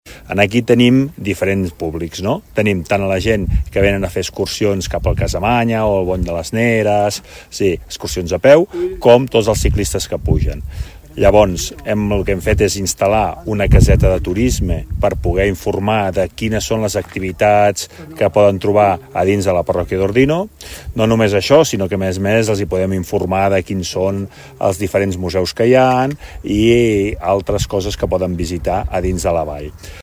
Així ho ha explicat el conseller de Turisme i Dinamització ordinenc, Jordi Serracanta.